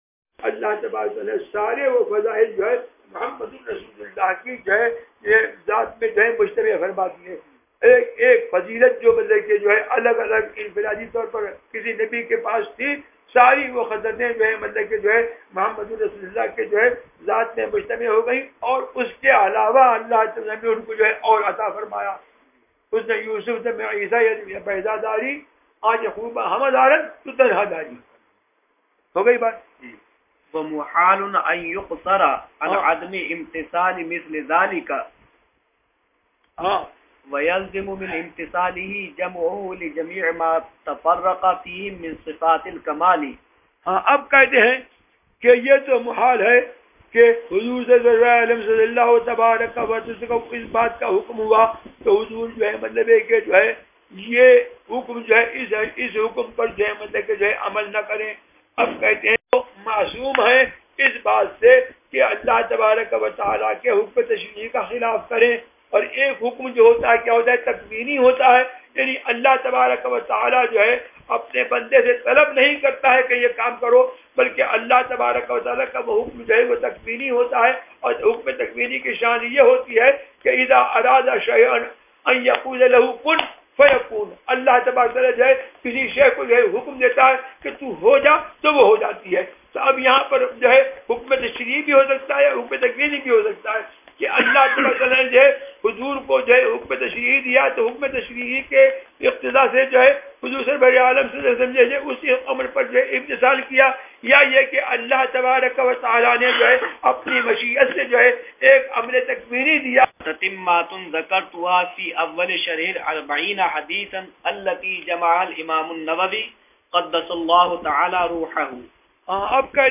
شرح الدر المنضود ZiaeTaiba Audio میڈیا کی معلومات نام شرح الدر المنضود موضوع تقاریر آواز تاج الشریعہ مفتی اختر رضا خان ازہری زبان اُردو کل نتائج 846 قسم آڈیو ڈاؤن لوڈ MP 3 ڈاؤن لوڈ MP 4 متعلقہ تجویزوآراء